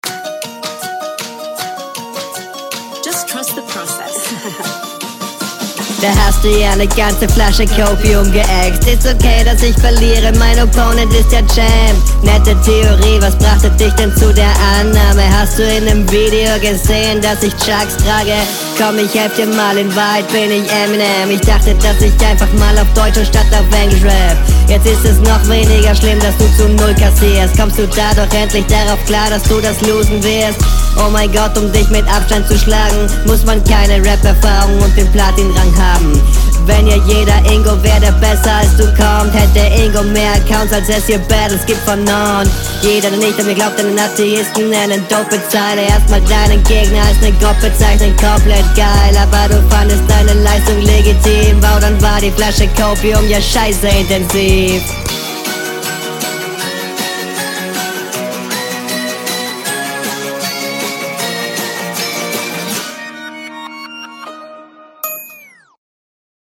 Flowlich klar vorne.